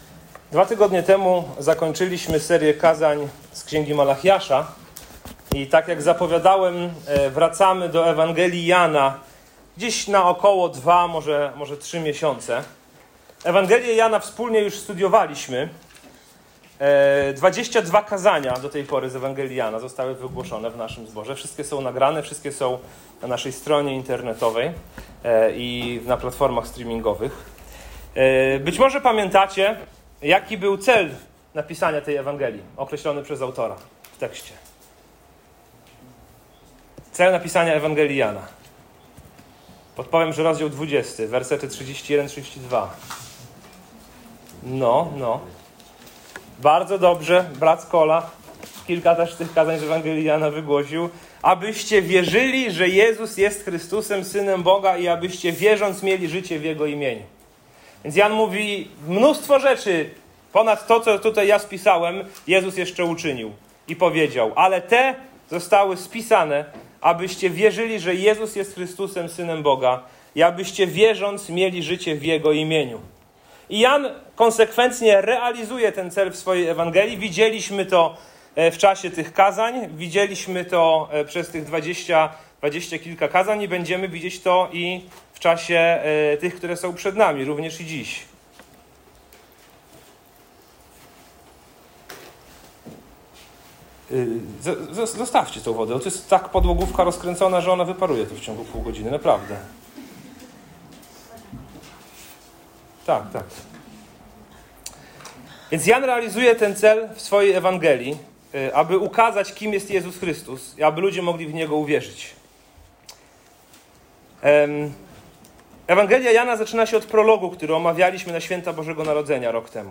W kazaniu omawiamy świadectwo Jana Chrzciciela, który wskazuje na Jezusa jako Baranka Bożego i Syna Bożego, przygotowując drogę przez chrzest pokuty i pokorną postawę.